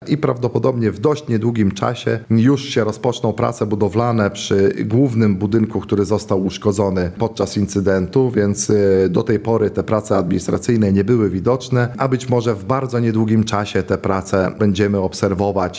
– W przyszłym tygodniu mamy otrzymać warunki zabudowy i budowa nowego domu dla poszkodowanej rodziny będzie mogła się rozpocząć w 2026 roku – mówi Radiu Lublin wójt Błaszczuk.